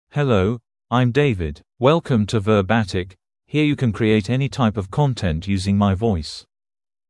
DavidMale English AI voice
David is a male AI voice for English (United Kingdom).
Voice sample
Male
David delivers clear pronunciation with authentic United Kingdom English intonation, making your content sound professionally produced.